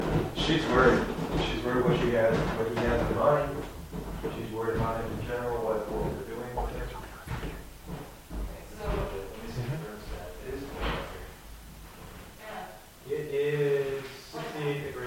A voice is captured on recorder